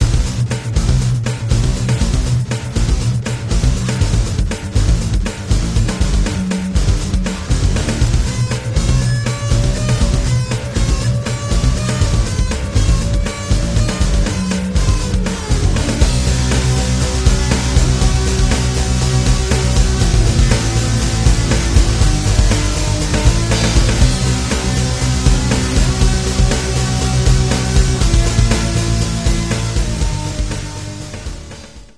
Clip 1 is the verse/chorus and clip 2 is the ending.